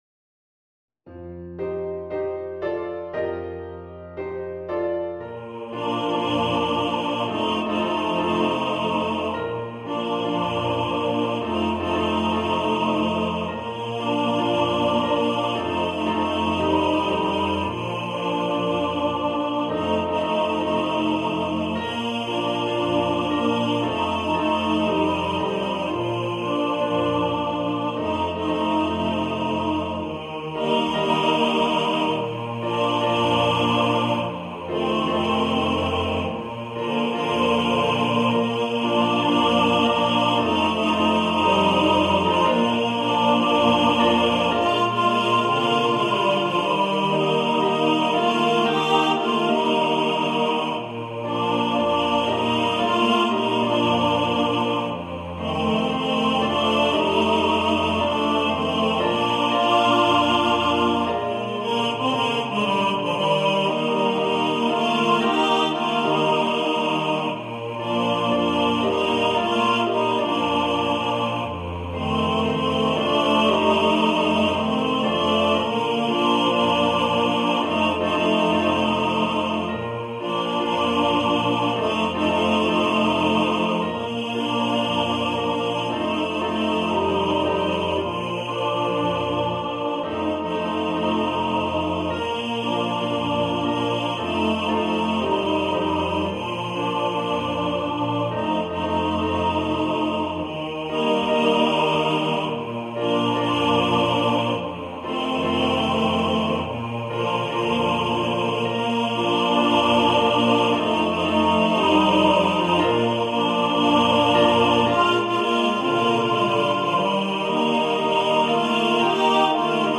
ein Schlager über das Fernweh und die Reiselust.